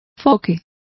Complete with pronunciation of the translation of jib.